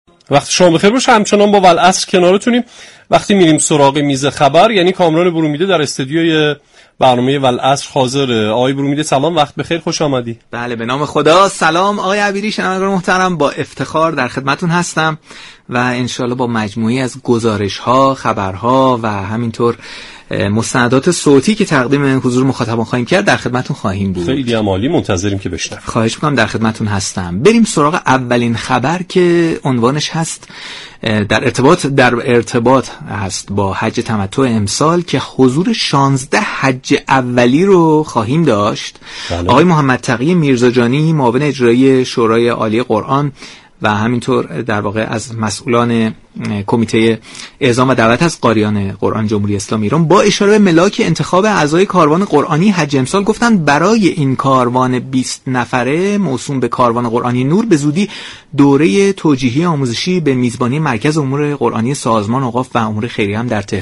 محمدتقی میرزاجانی، معاون اجرایی شورای عالی قرآن در بخشی از برنامه والعصر رادیو قرآن گفت: حج ابراهیمی فرصتی بی‌بدیل برای نمایش دستاورد‌ها و معرفی توفیقات ایران اسلامی ازجمله در عرصه انس با قرآن كریم، همچنین دفع شبهات و رفع اتهامات است.